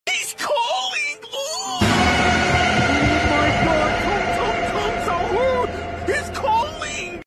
Fool friends with ultra-realistic call